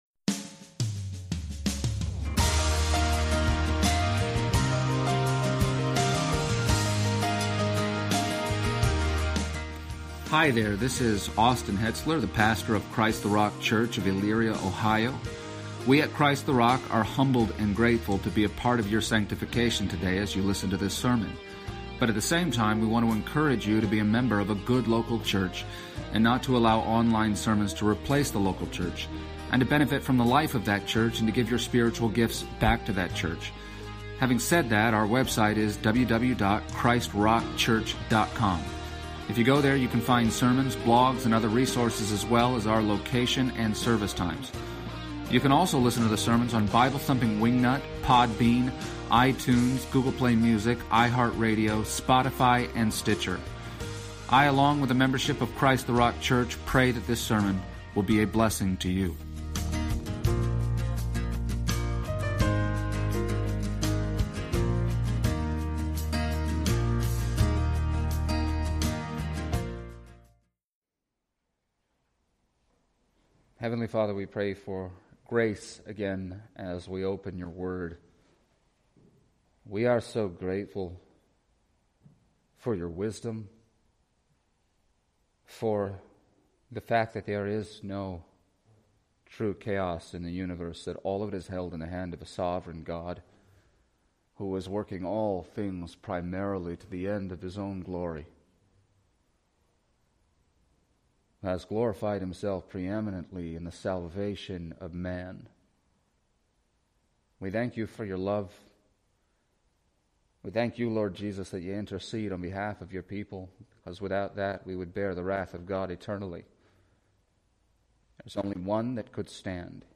Passage: John 17:6-11 Service Type: Sunday Morning